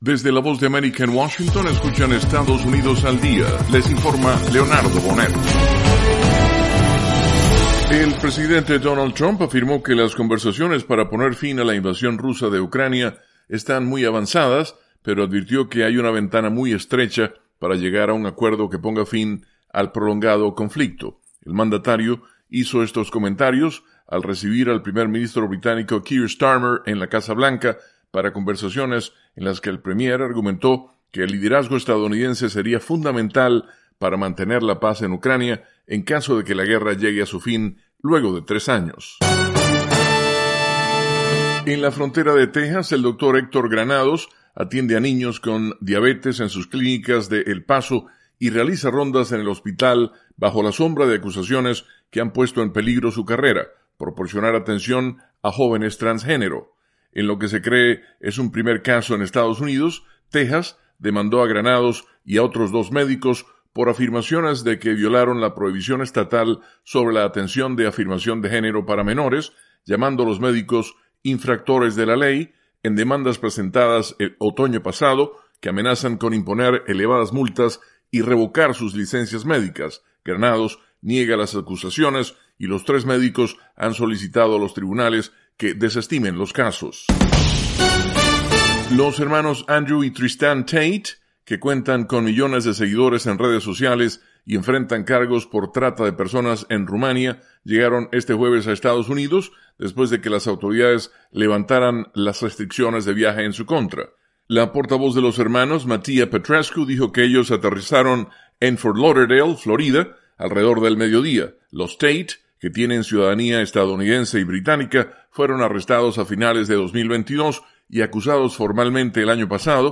Estados Unidos al Día: Con algunas de las noticias nacionales más importantes de las últimas 24 horas.